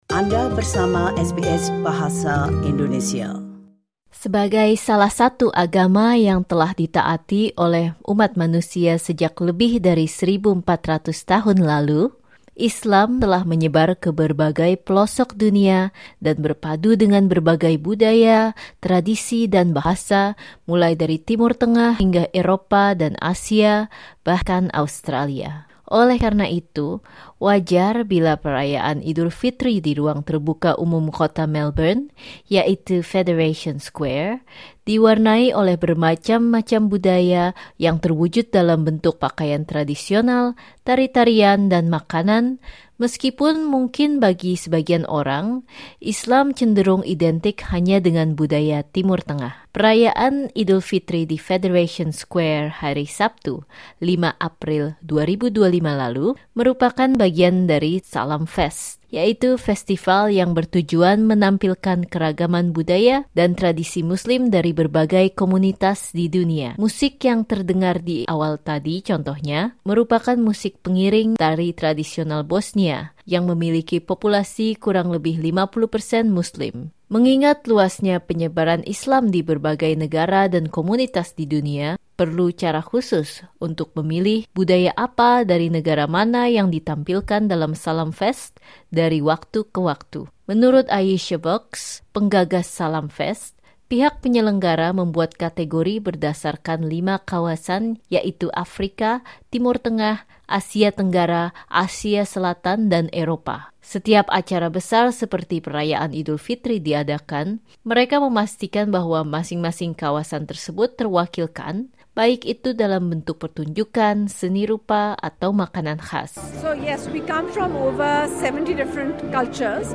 Listen to SBS Indonesian's conversation with those involved in the festival.